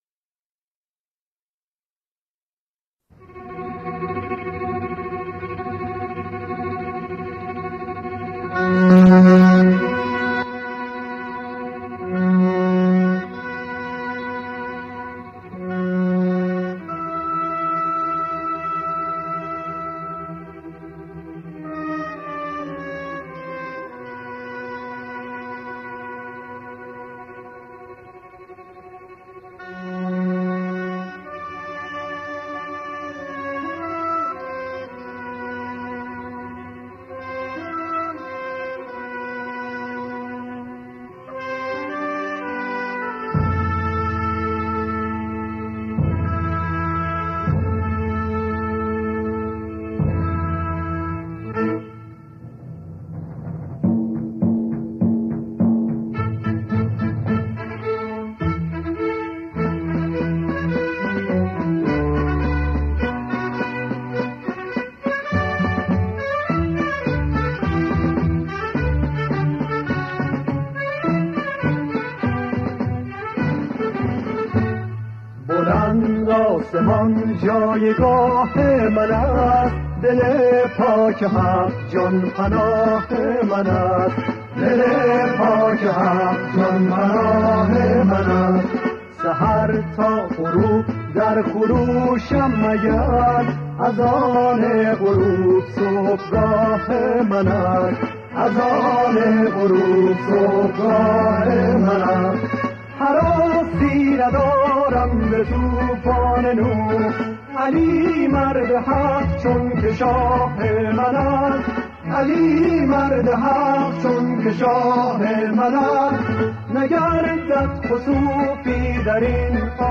سرود قدیمی
گروهی از همخوانان